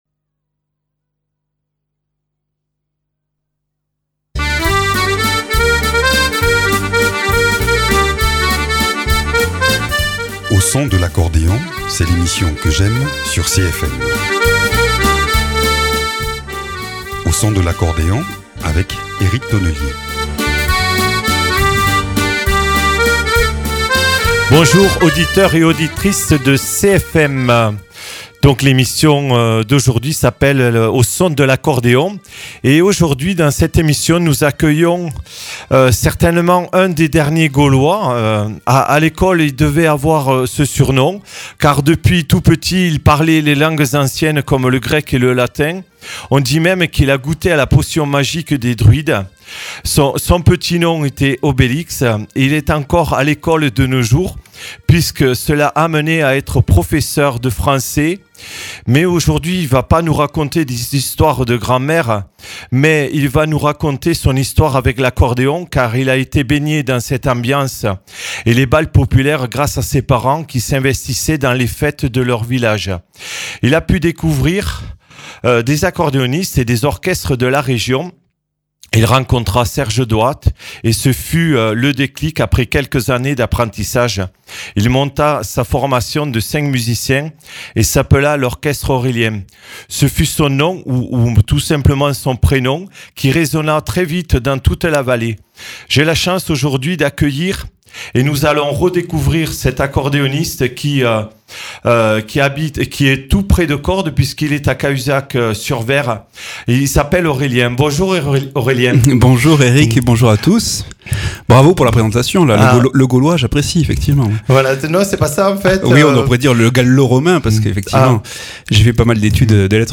Avec nous aujourd’hui un accordéoniste installé dans le Tarn !